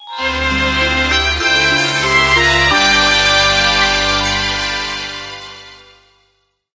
FanfaresEdit